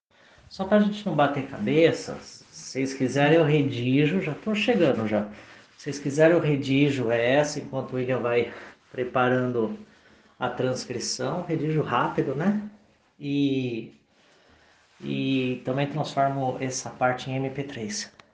Ato ocorreu em evento sobre 100 dias de Governo
O aviso foi feito em cerimônia de apresentação de balanço dos 100 dias de gestão.